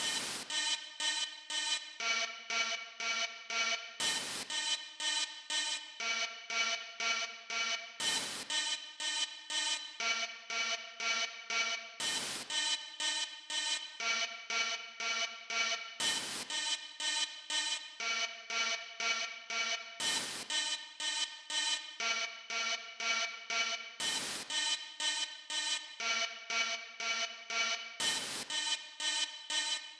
These are the chord sounds I was then able to create by resampling small, textual parts from the granulator into the Ableton sampler.
chords-3.wav